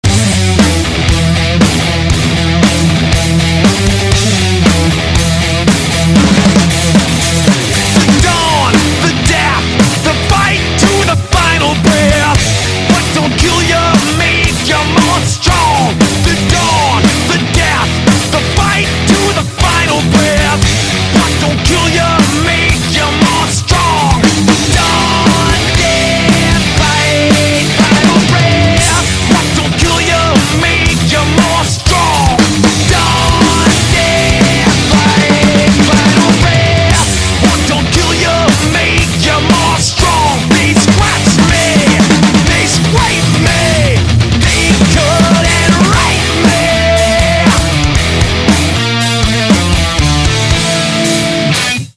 • Качество: 128, Stereo
мужской вокал
громкие
Trash metal